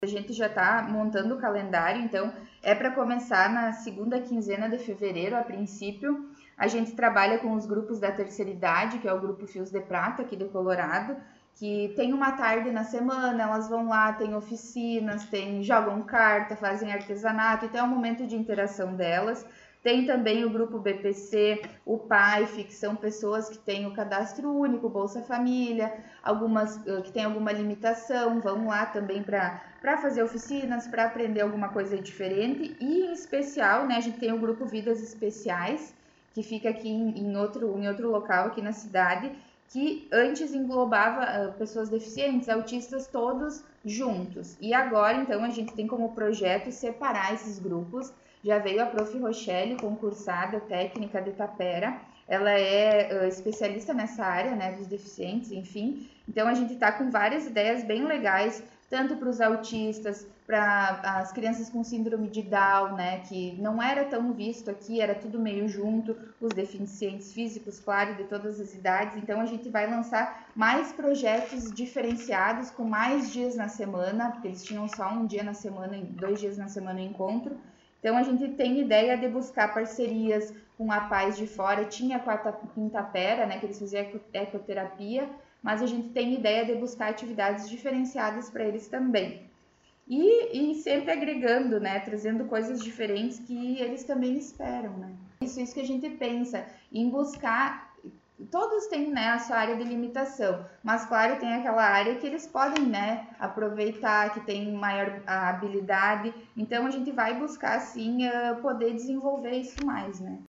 Secretária de Ação Social, Habitação e Saneamento concedeu entrevista